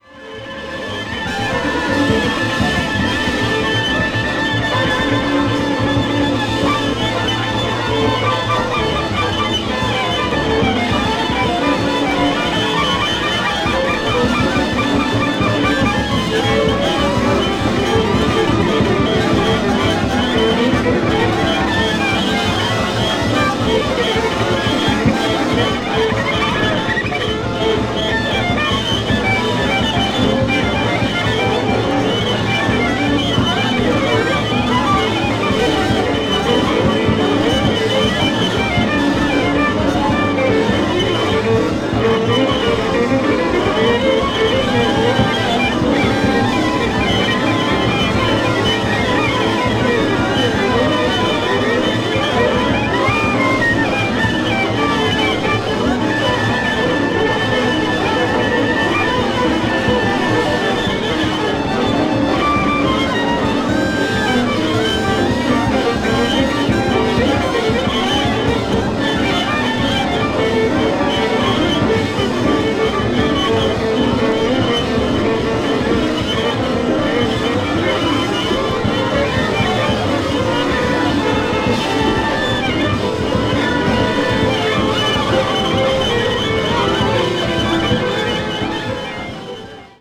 強烈。
avant-jazz   free improvisation   free jazz   jazz orchestra